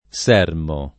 sermo [ S$ rmo ] → sermone